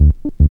02SYN.LICK.wav